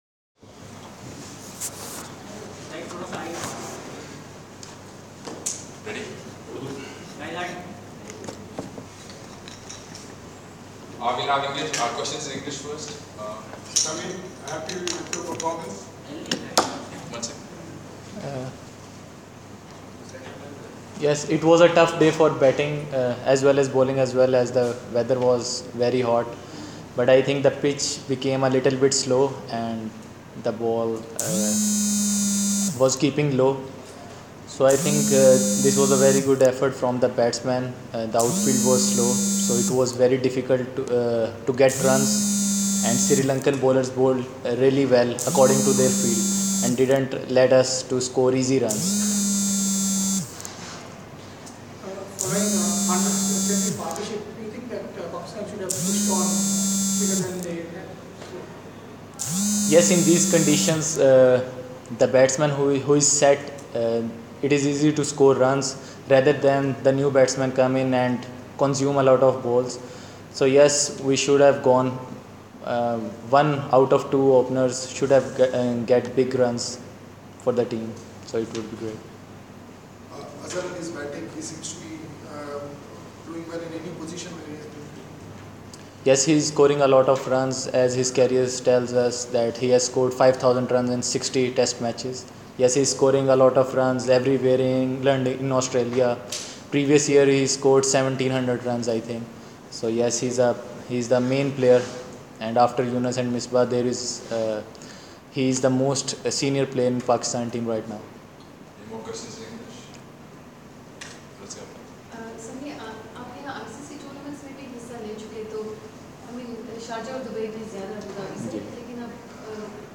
Sami Aslam press conference after day three of the first test at Abu Dhabi